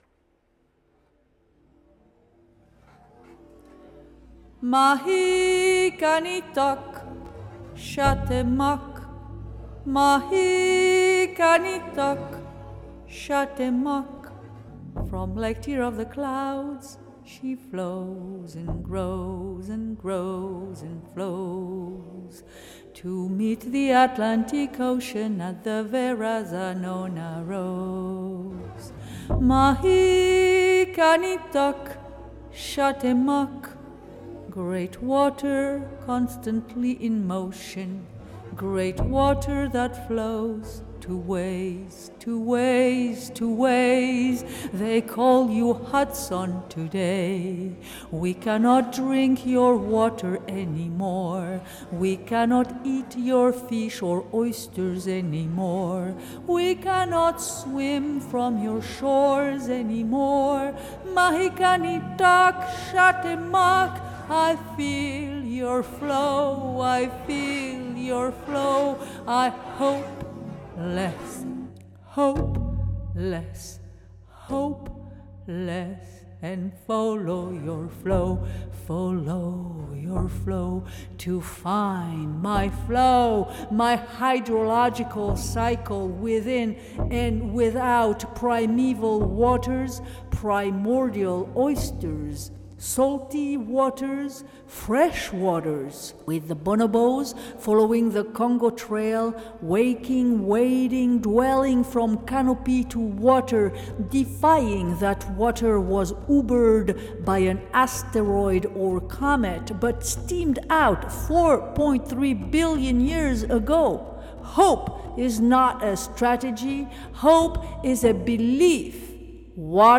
Karstic-Action Be Like Water was broadcast from my studio in Bay Ridge — in the southwest of Brooklyn, located on a terminal moraine created by receding glaciers around 13,000 years ago along the Verrazano Narrows.